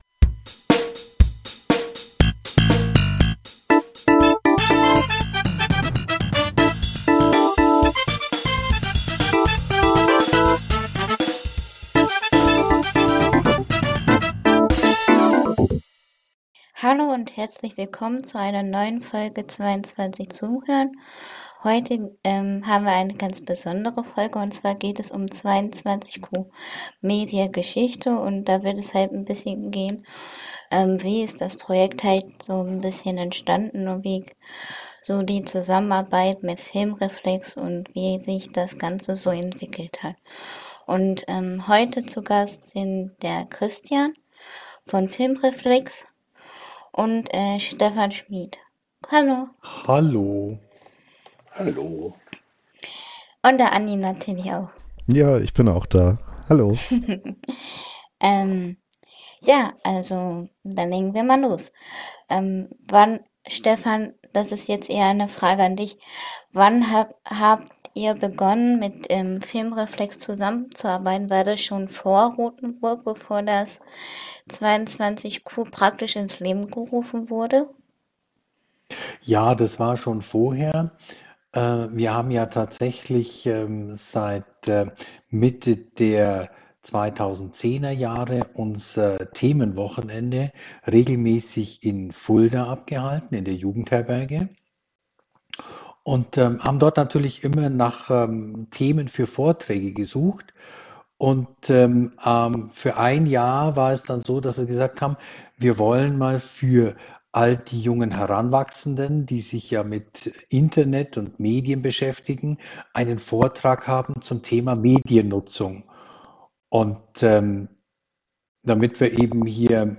Ein Gespräch über die Anfänge, das bisher Erreichte und die Zukunft von 22QMedia.